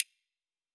Sound / Effects / UI